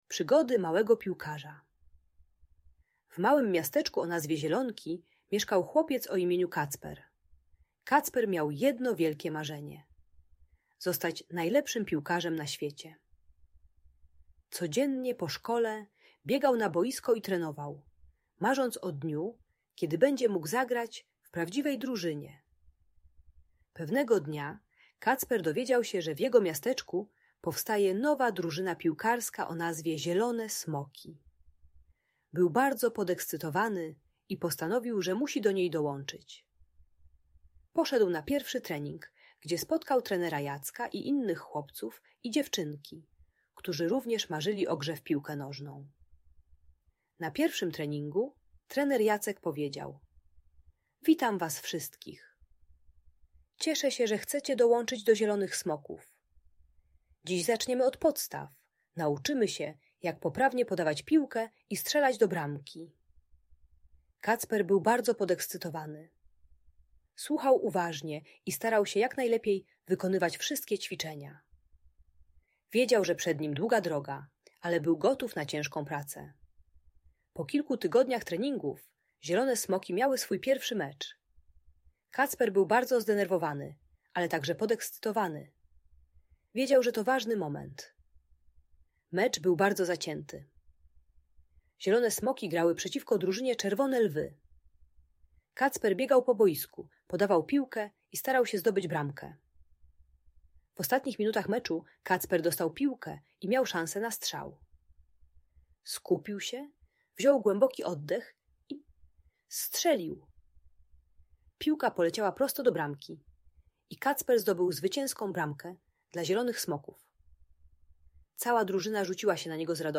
Przygody Małego Piłkarza - story o marzeniach i uczciwości - Audiobajka